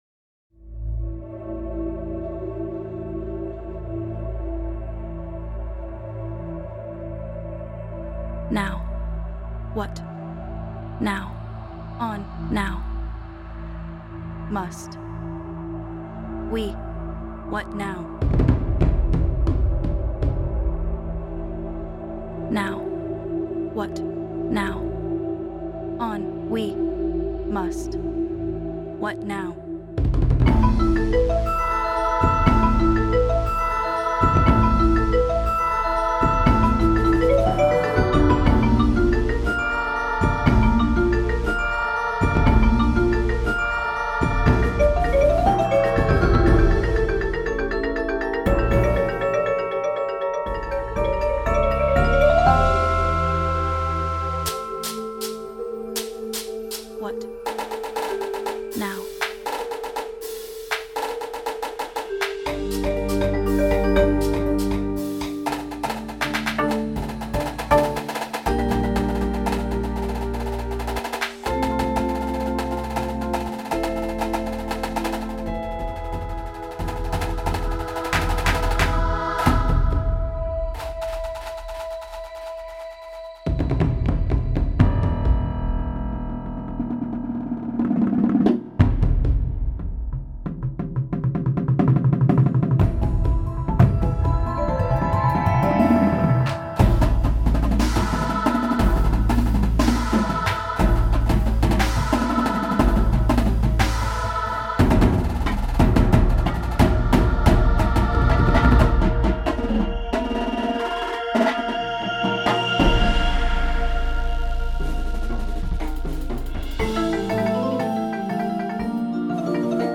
Indoor Percussion Shows
• Snares
• Tenors
• 5 Bass Drums
• Cymbals
Front Ensemble
• 4 Marimbas w/ 1 mounted xylo
• 4 Vibes w/ 1 mounted crotales
• Glockenspiel
• 1 or 2 Synths
• Timpani
• Drumset